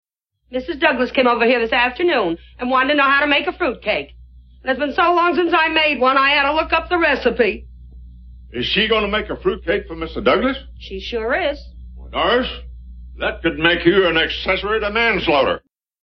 Fruit cake mans laughter
Category: Television   Right: Personal